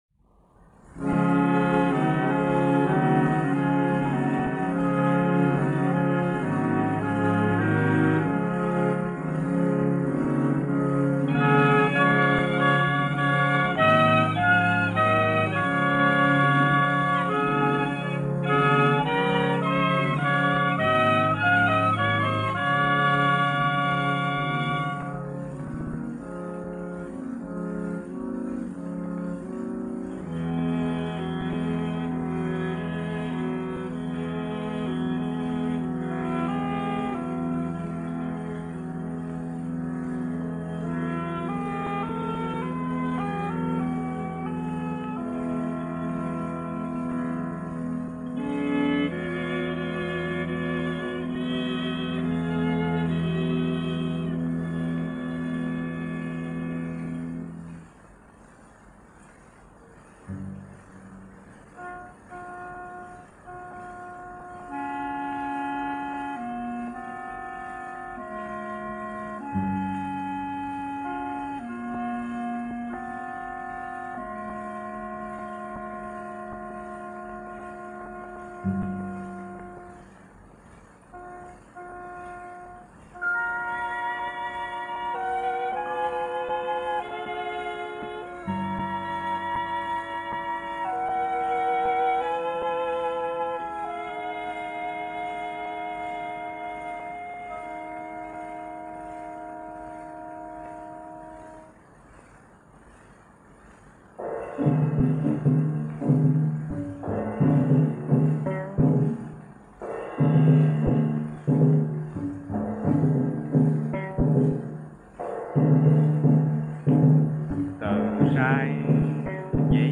Piano
gramophone records
the world premier recording